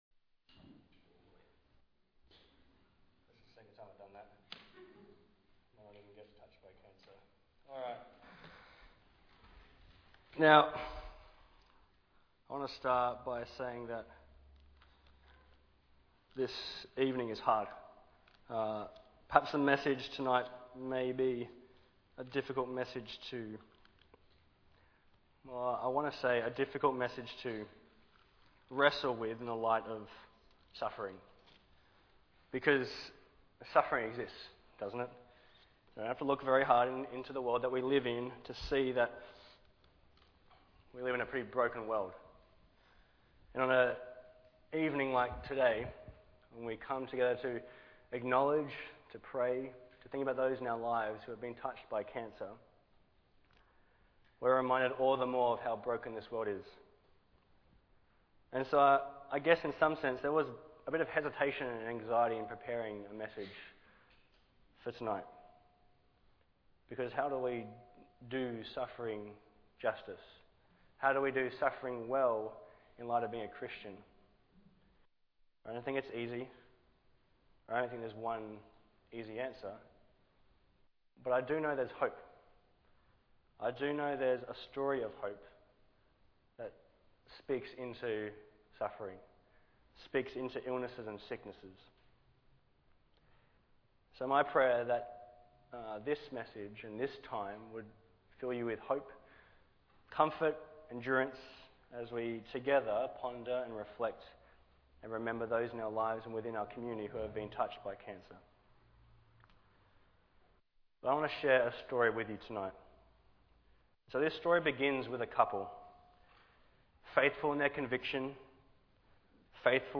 Series: Guest Preachers